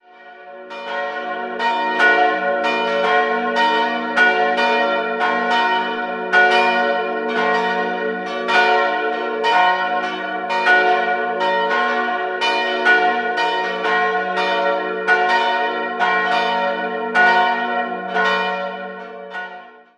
Sie besitzt keinen Turm - zum Gottesdienst rufen daher die Glocken der Alten Kirche. Innenansicht wird noch ergänzt. 3-stimmiges Geläute: fis'-ais'-d'' Die beiden größeren Glocken wurden 1949 von der Firma Hamm in Regensburg gegossen, die kleine stammt aus der Gießerei Kopfmüller (Eichstätt) aus dem Jahr 1886.